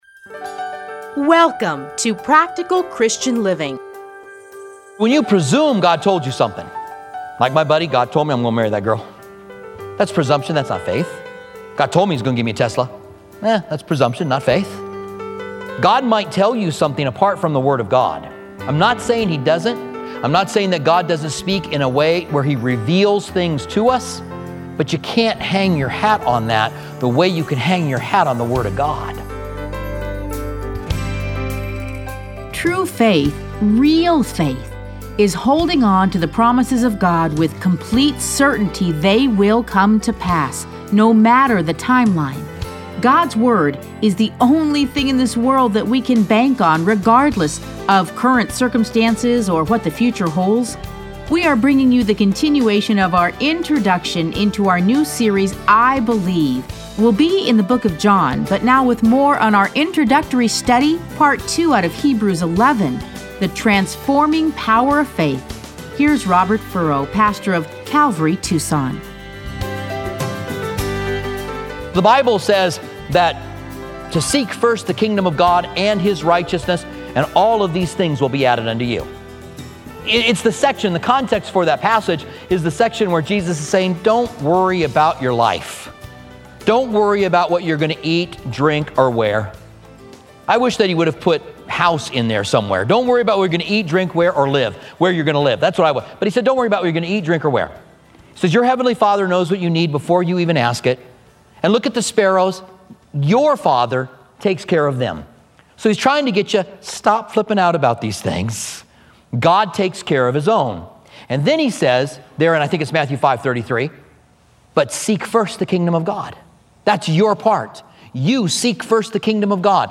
Listen to a teaching from Hebrews 11.